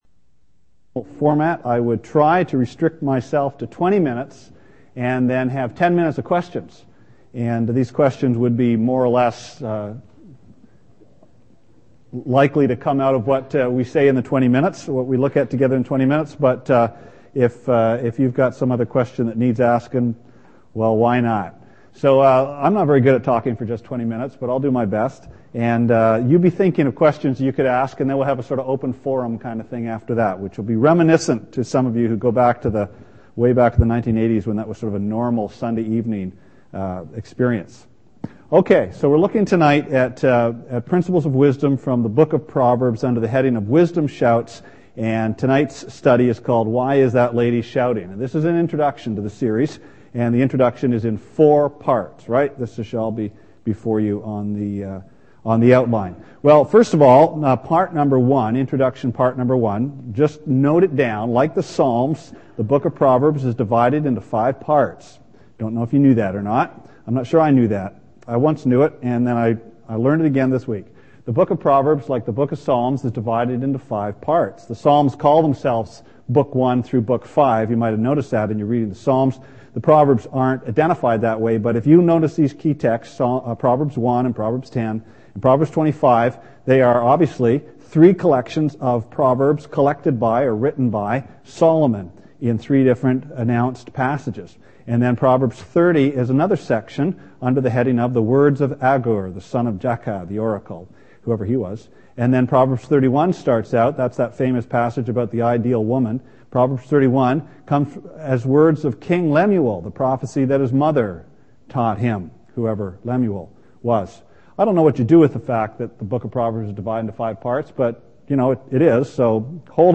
Why Is That Lady Shouting? Part 1 of a Sunday evening series on Proverbs.
Sermon Archives Mar 9